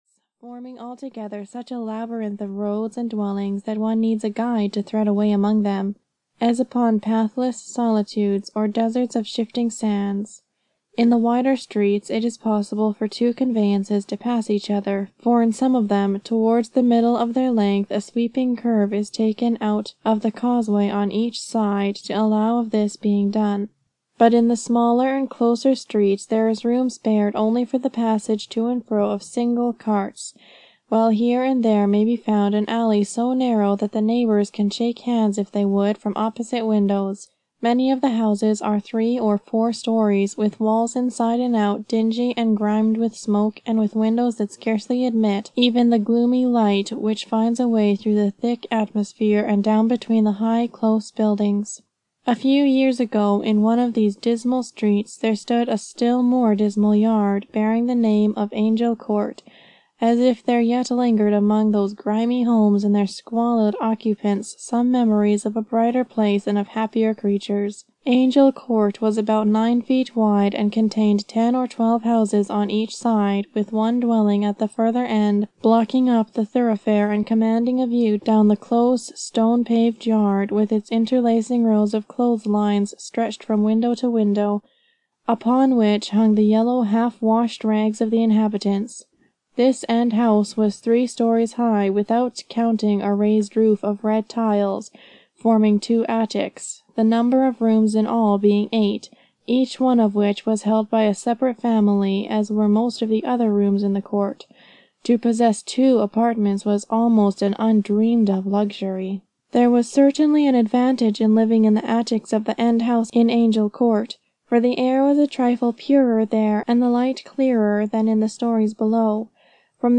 Little Meg's Children (EN) audiokniha
Ukázka z knihy